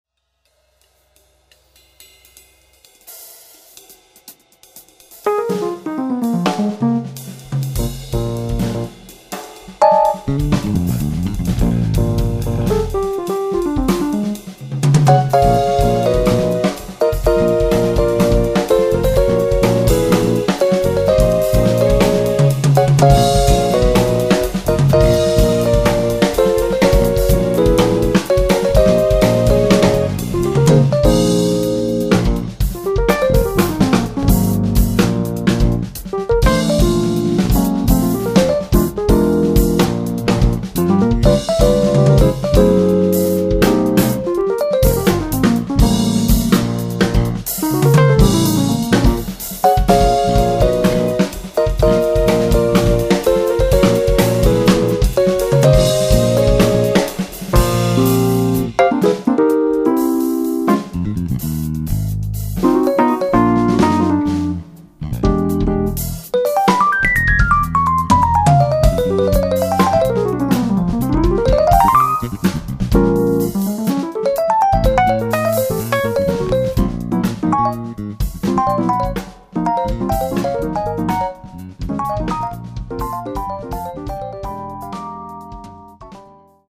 sax alto
piano, piano elettrico
contrabbasso, basso elettrico
batteria